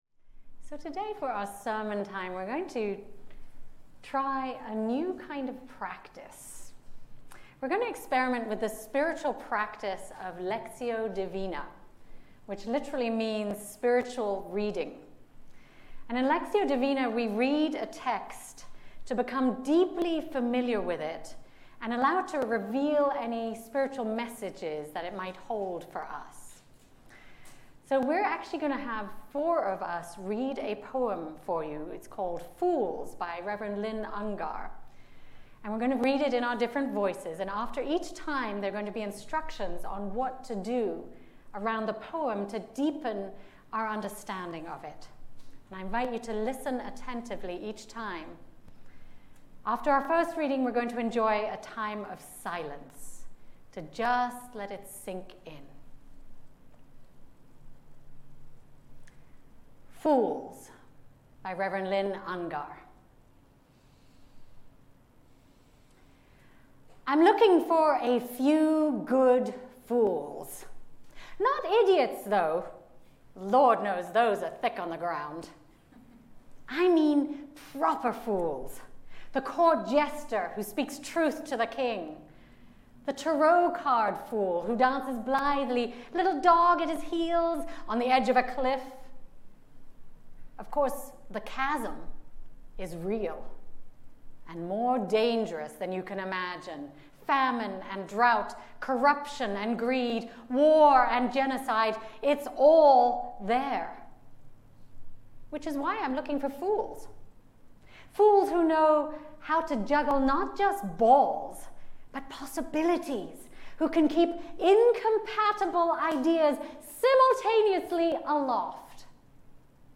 Sermon.m4a